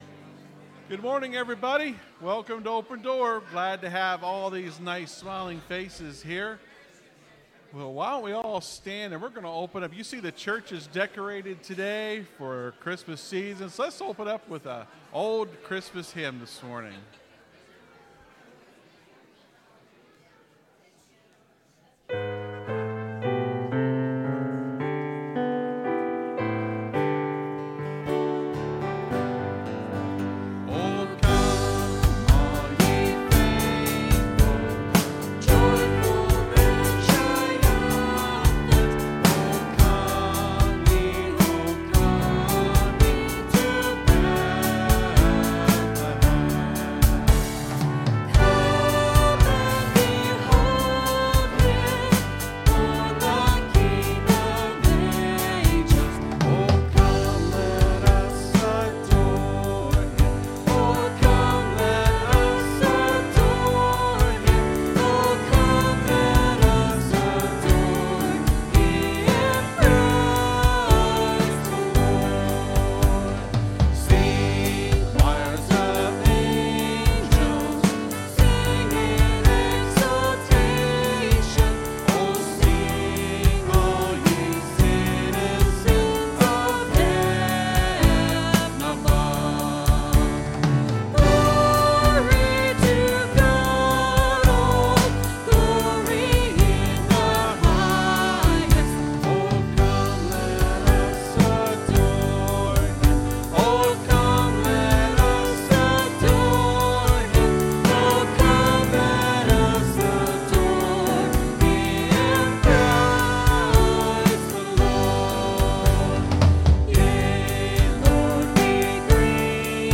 (Sermon starts at 28:15 in the recording).